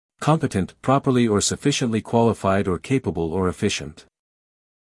英音/ ˈkɒmpɪtənt / 美音/ ˈkɑːmpɪtənt /